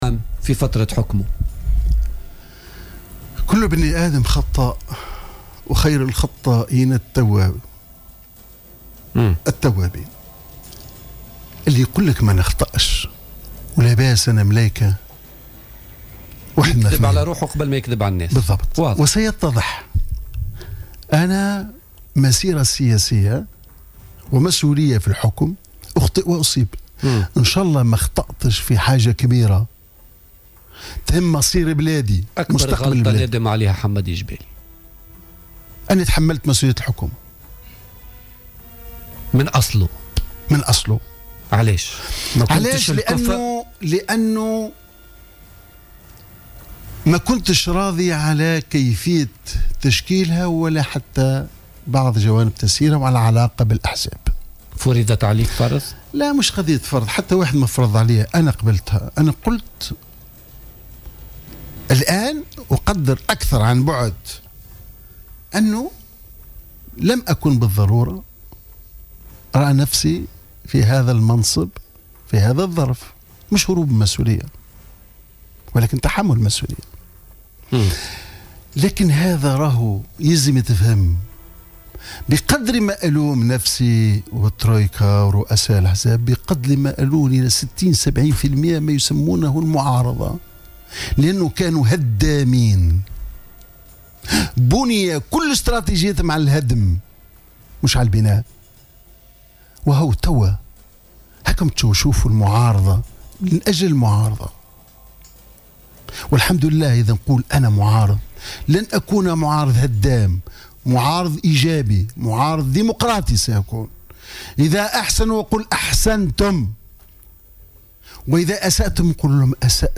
قال حمادي الجبالي ضيف برنامج "بوليتيكا" اليوم إنه أخطأ في تحمله لمسؤولية رئاسة الحكومة في عهد الترويكا.